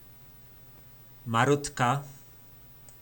Slovník nářečí Po našimu